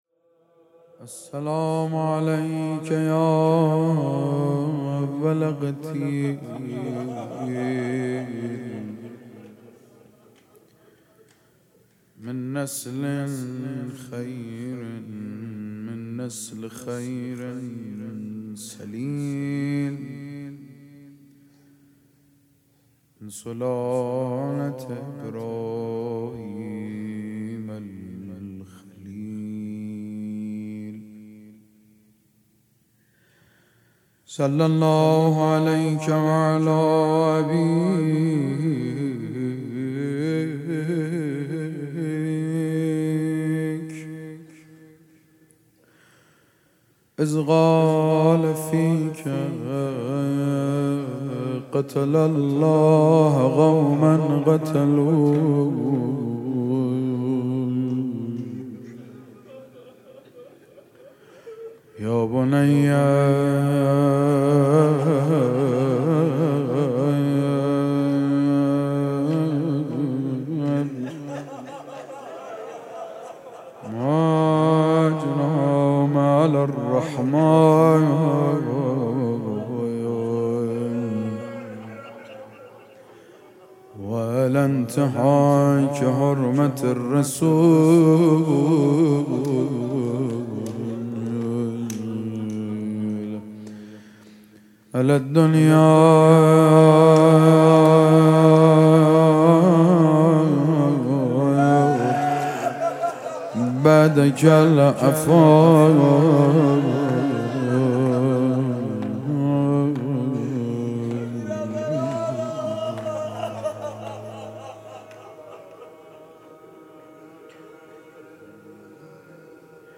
مداح
مناسبت : شب دوم محرم